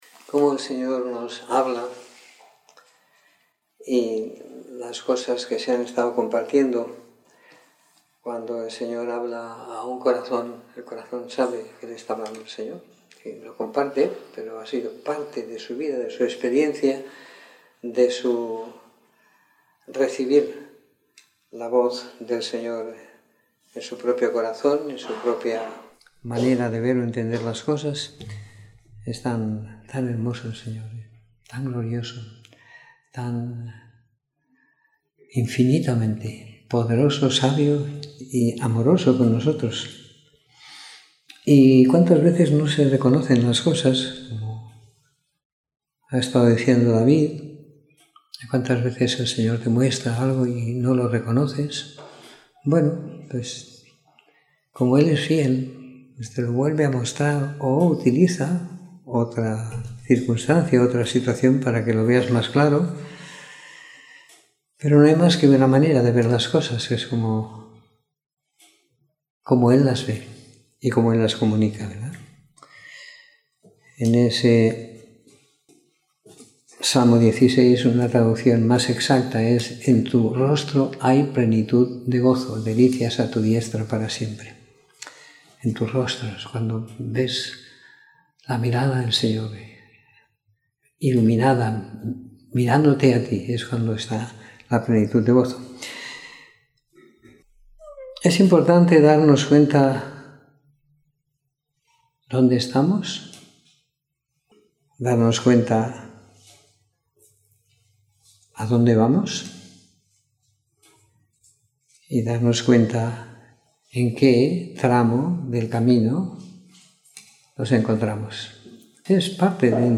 Domingo por la Tarde . 18 de Marzo de 2018